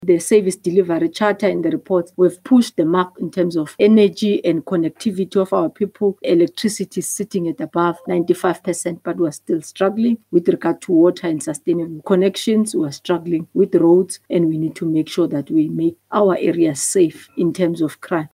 Sy sê verlede jaar se beoordeling toon ‘n effense afname tot 107 in mediumrisiko-munisipaliteite, wat bemoedigend is. Nkadimeng het op ‘n nuuskonferensie oor die regering se steun vir munisipaliteite gesê burgers verdien dienslewering soos huise wanneer fondse toegeken word: